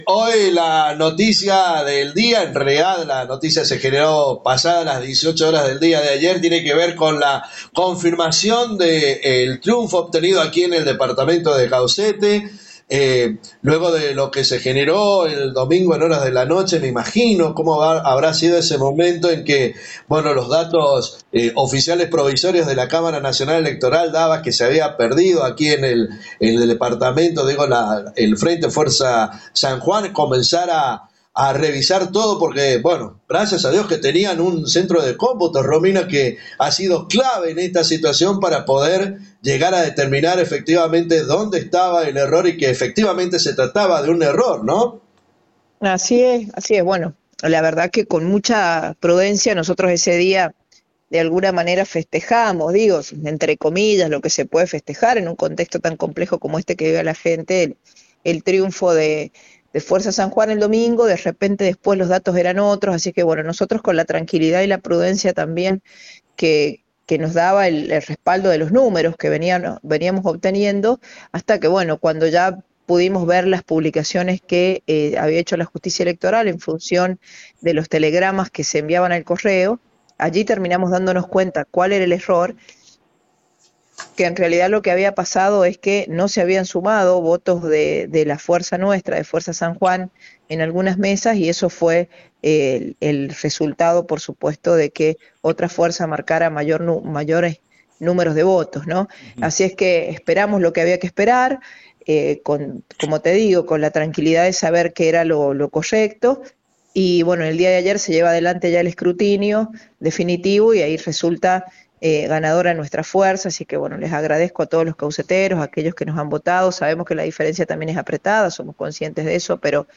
La intendenta de Caucete Lic. Romina Rosas, charló con Radio Genesis , para referirse al resultado obtenido en las elecciones del domingo pasado,ademas del análisis electoral provincial y departamental, se refirió tambien a la elección nacional,